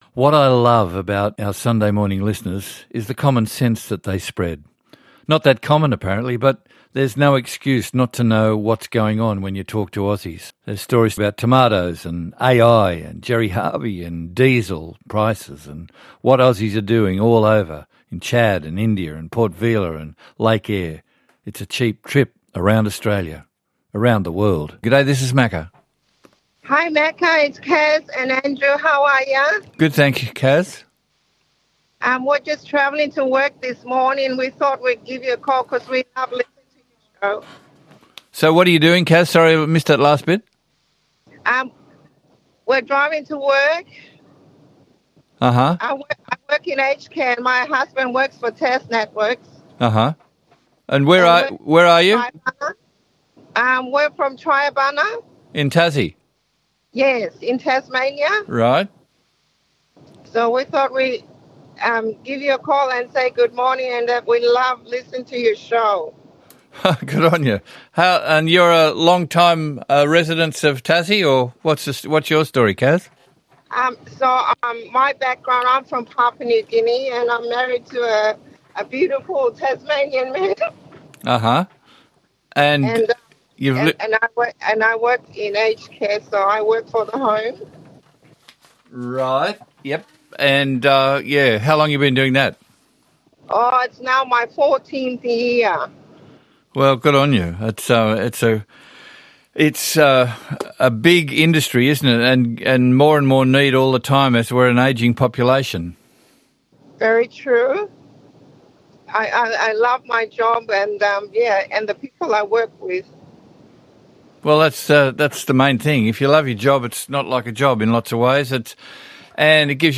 'Some of the most informative and entertaining calls from this week's Australia All Over with Macca.